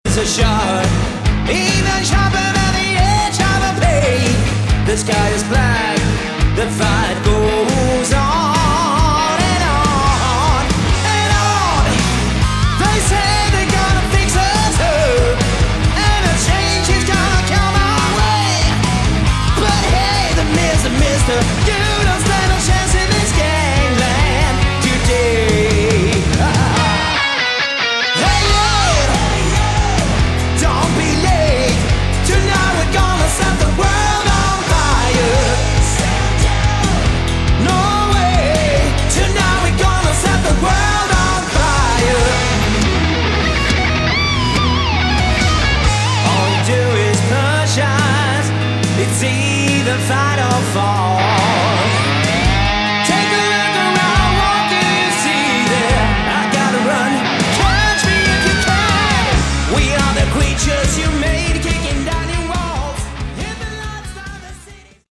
Category: Hard Rock / Melodic Metal
vocals
lead guitars
bass
drums